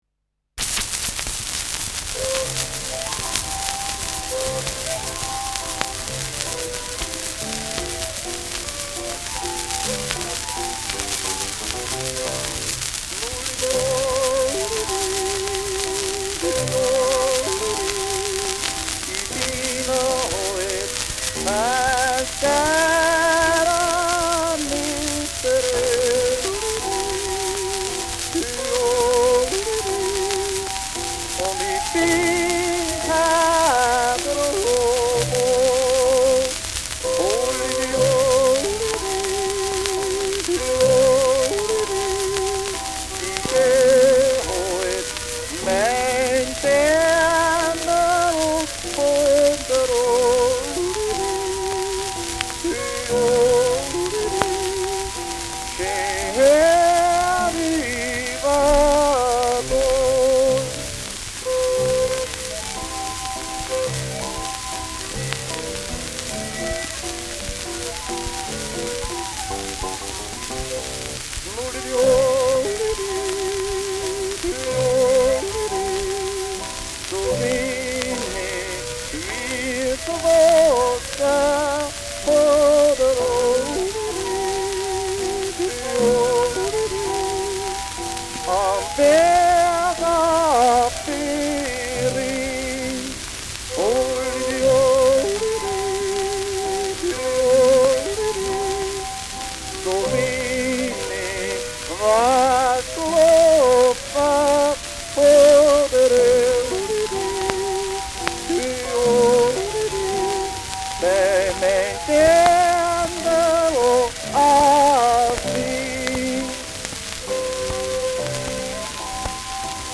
Schellackplatte
starkes Rauschen : starkes Knistern : abgespielt : leiert